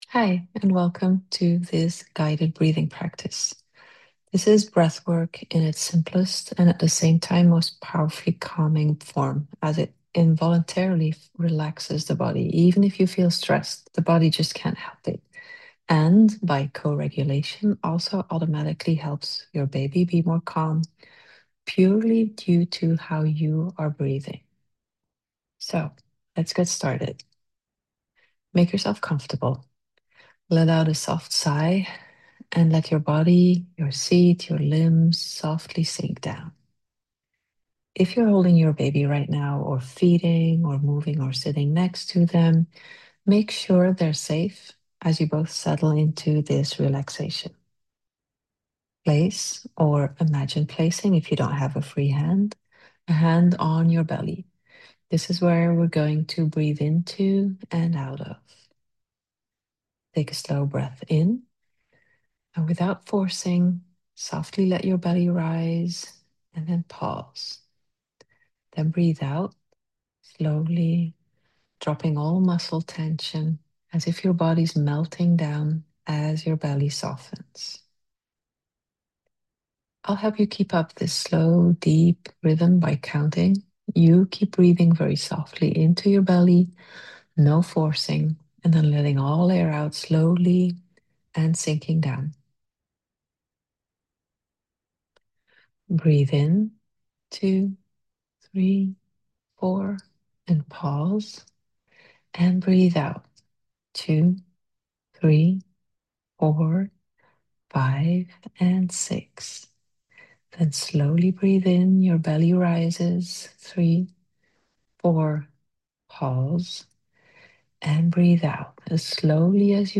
BONUS Download (Guided Breathing)